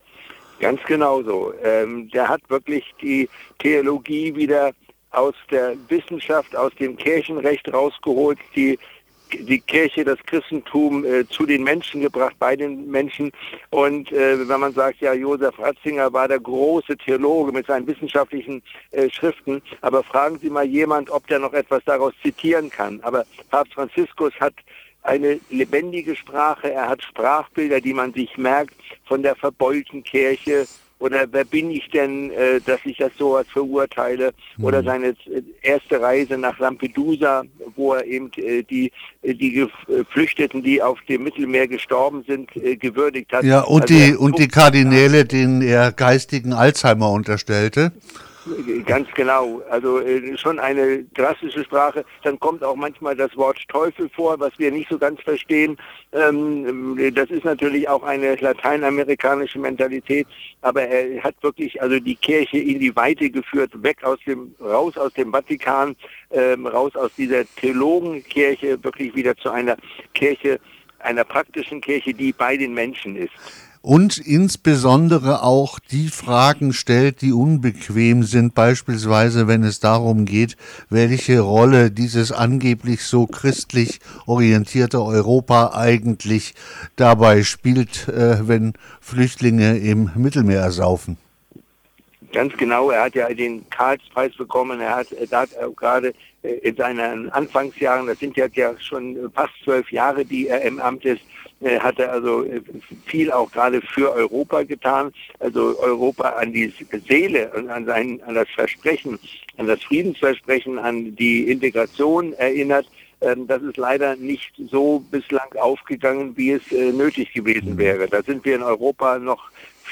Interview zu Papst Franziskus